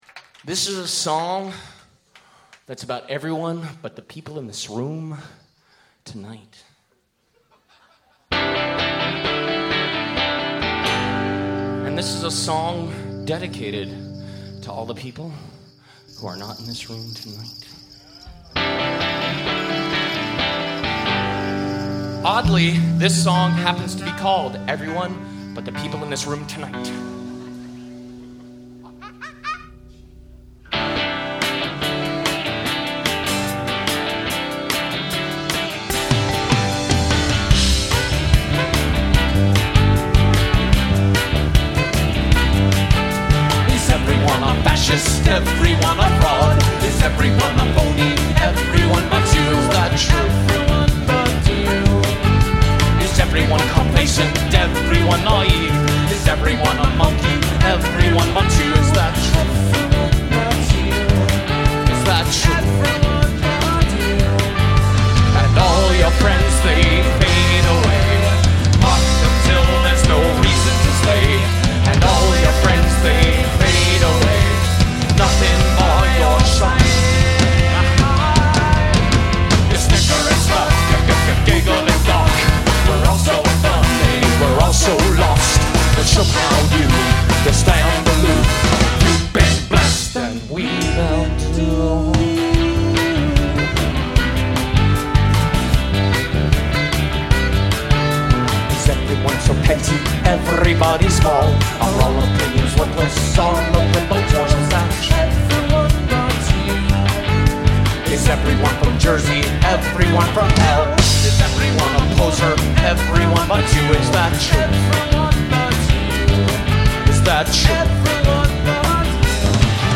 UNLEASHED AT ARLENE GROCERY) New York, NY 6-12-02
trombone
saxophone
A jubilant explosion.